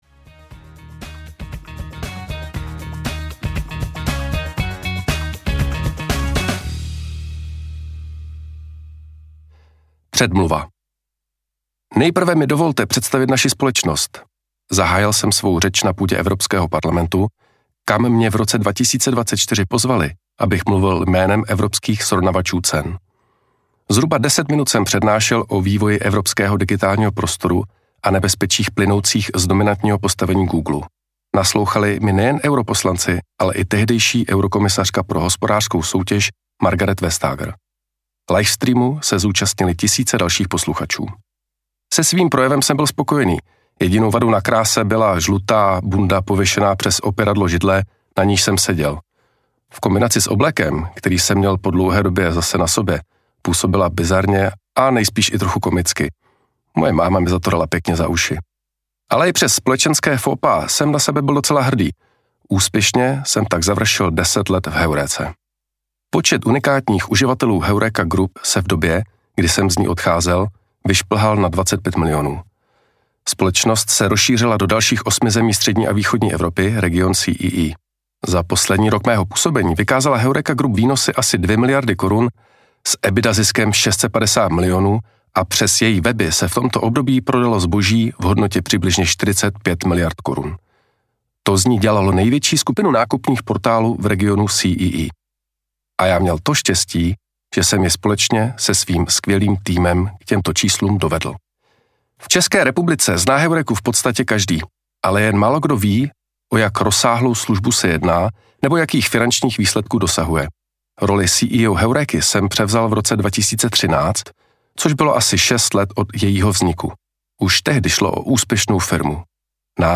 Heuréka! audiokniha
Ukázka z knihy